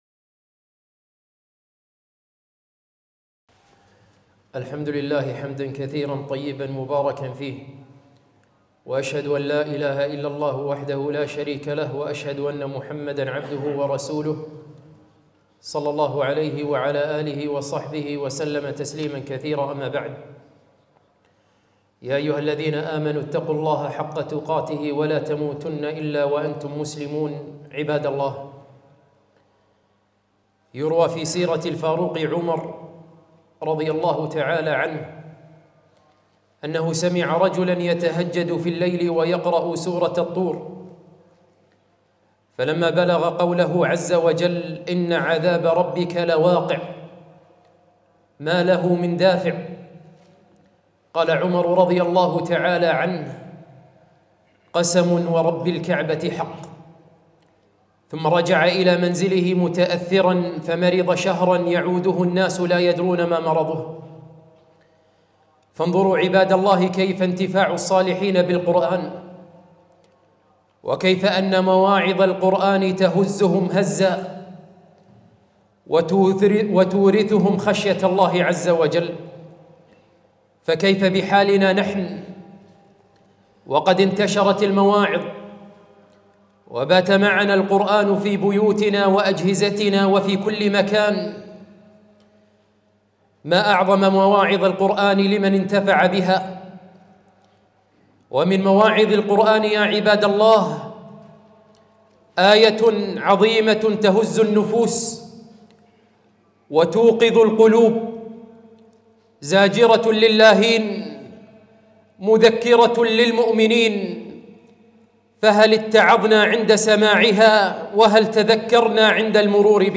خطبة - متى تلين قلوبنا؟